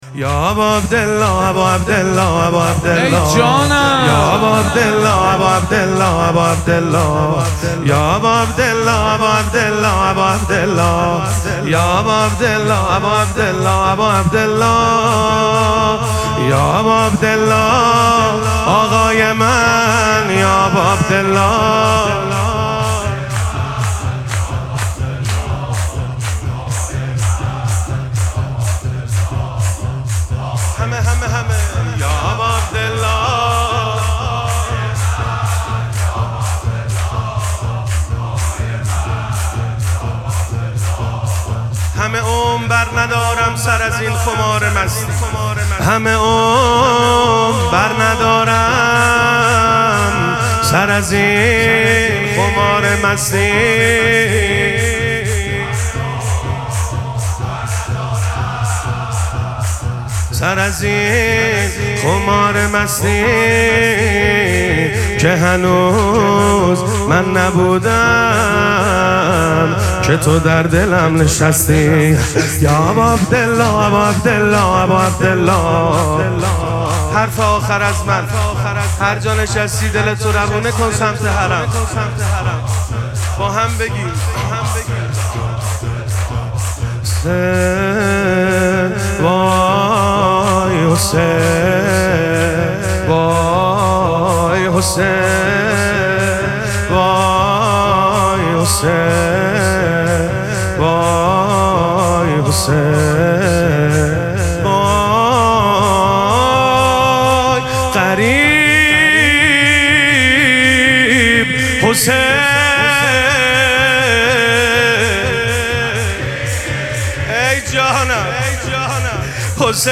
مراسم مناجات شب هجدهم ماه مبارک رمضان
حسینیه ریحانه الحسین سلام الله علیها
شور